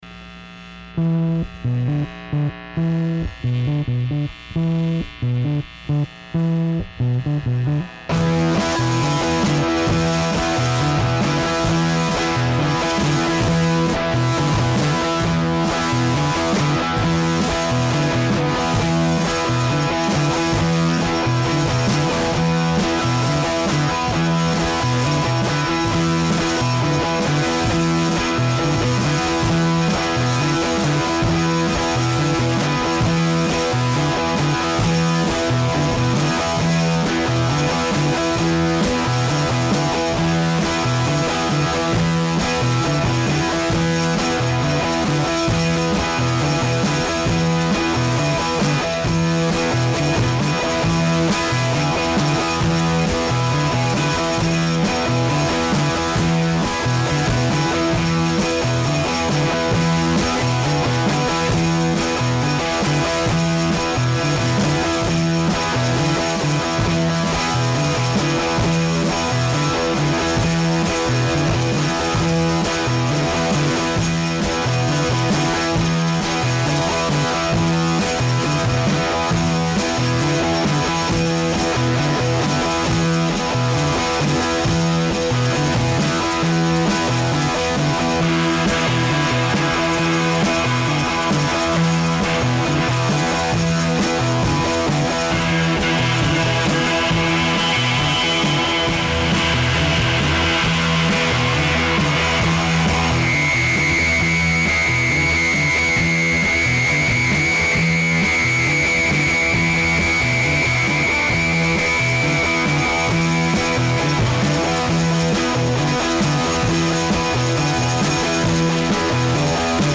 psychedelic
psych rockers
druggy thud